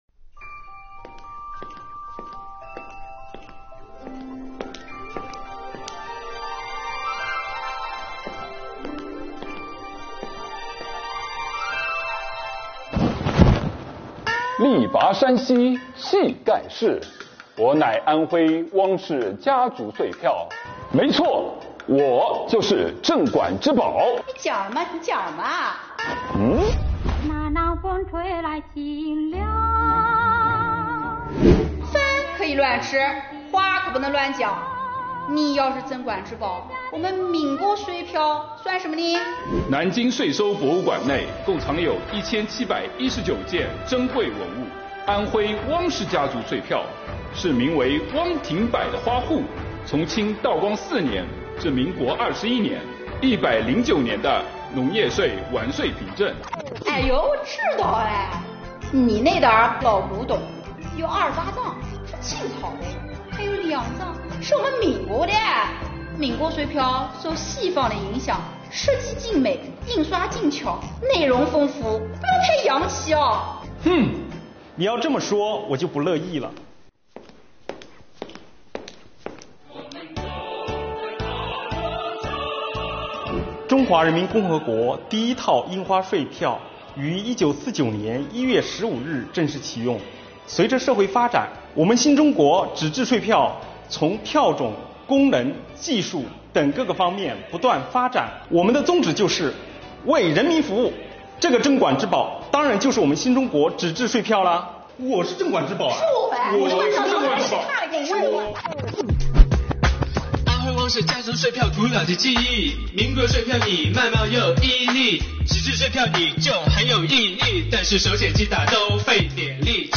每个人物都具有鲜明的个性特质和语言特色，有趣又有料，最后落脚在“为人民服务”上，升华了主题。